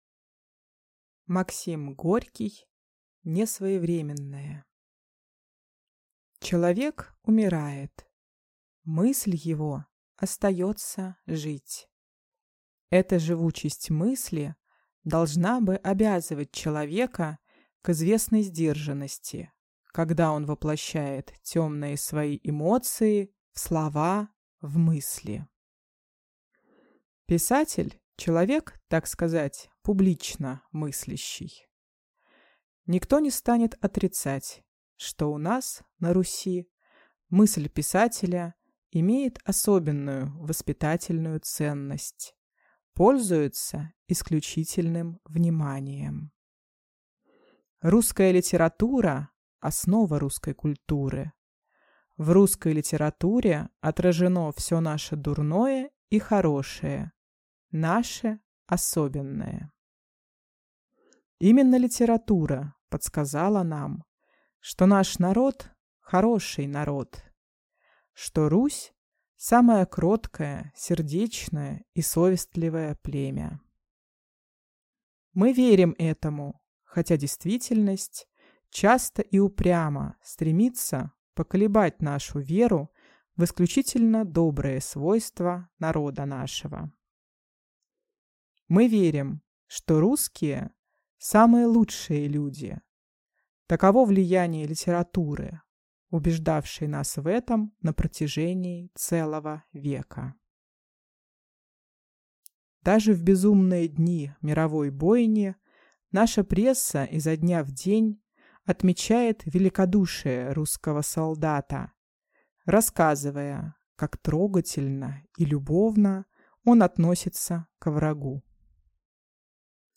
Аудиокнига Несвоевременное | Библиотека аудиокниг
Прослушать и бесплатно скачать фрагмент аудиокниги